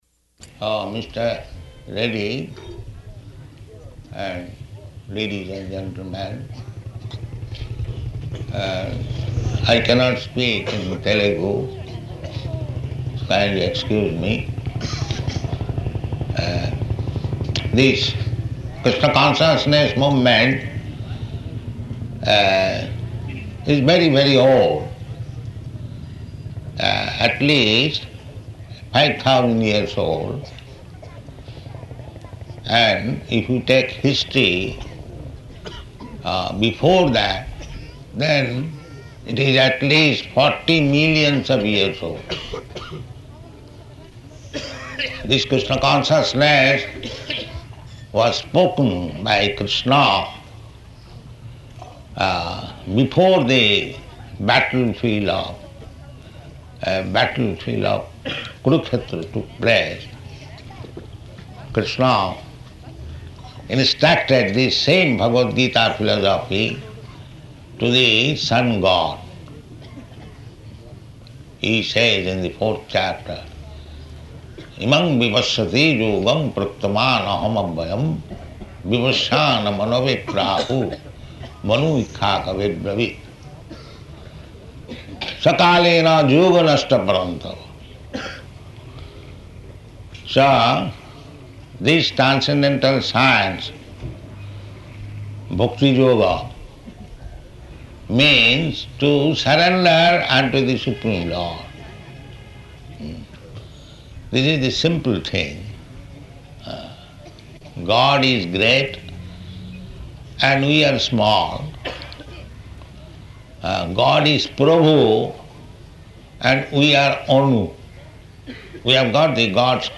Lecture
Lecture --:-- --:-- Type: Lectures and Addresses Dated: January 4th 1976 Location: Nellore Audio file: 760104LE.NEL.mp3 Prabhupāda: Uh...